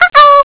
Cŕŕn Prisla sprava - zvuk z ICQ 0:01